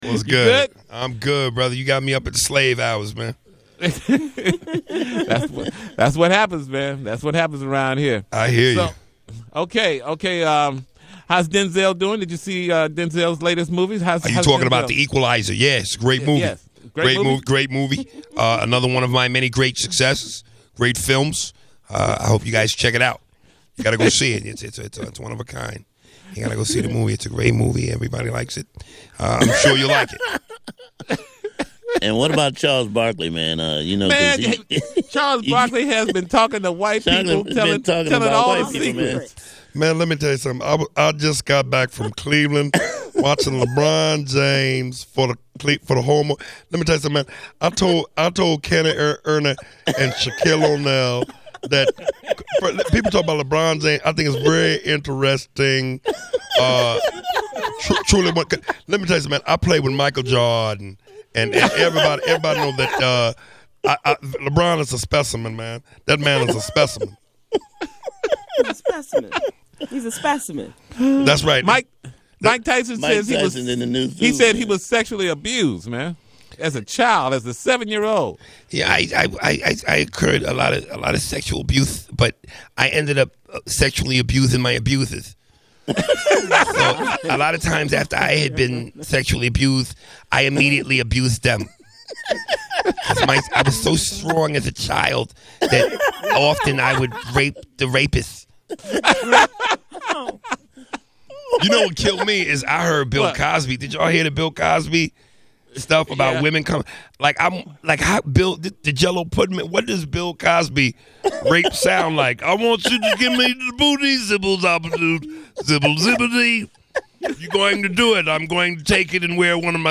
Comedian and Man of Many Voices Aries Spears brings Denzel, Charles Barkley, Jay Z, Mike Tyson, Bill Cosby and more (plus the funny!) to The Tom Joyner Morning Show Friday October 31st!